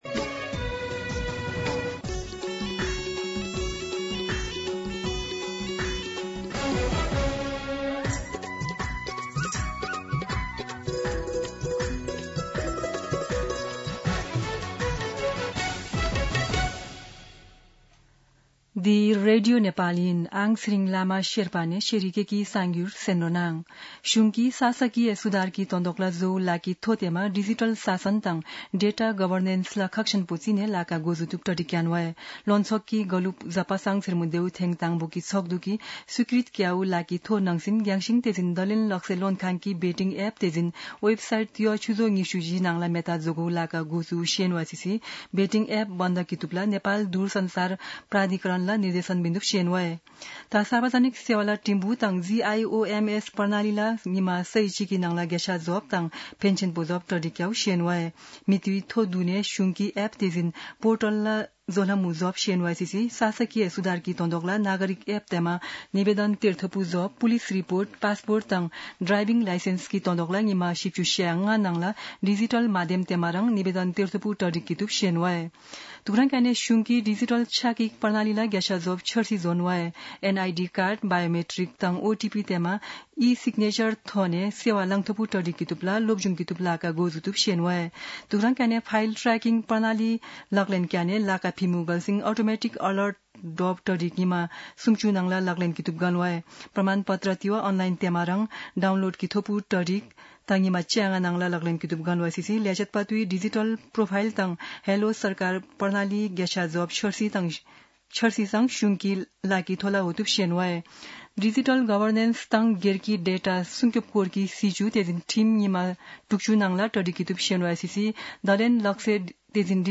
शेर्पा भाषाको समाचार : १७ चैत , २०८२
Sherpa-News-12-17.mp3